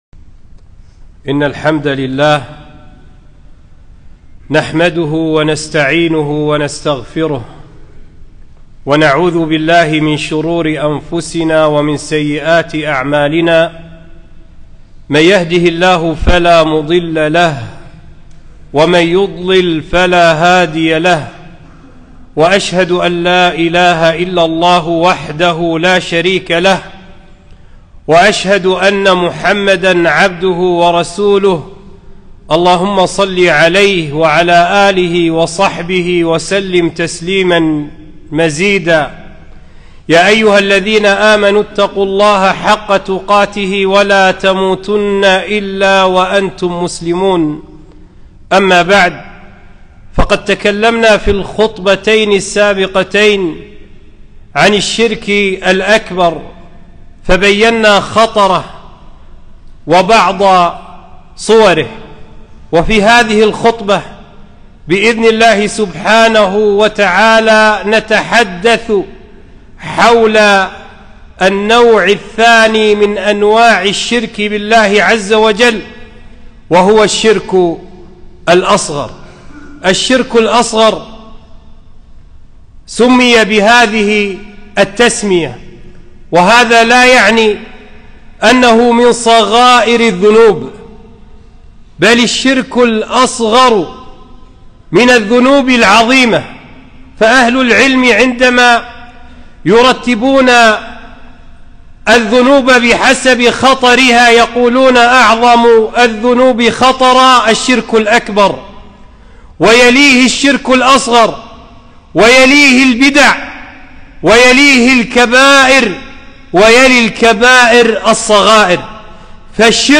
خطبة - الشرك الأصغر عظيم خطره وبعض صوره